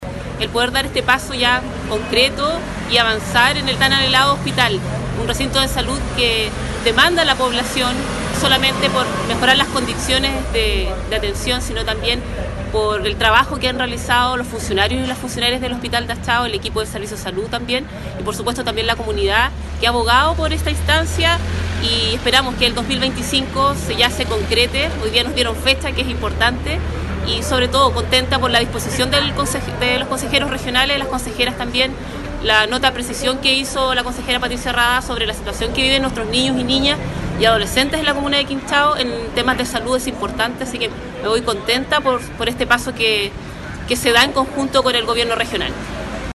La concejala de la comuna de Quinchao, Natalia Altamirano, también expresó su satisfacción por el primer paso que da el futuro hospital de Achao, al obtener los recursos para la compra del terreno.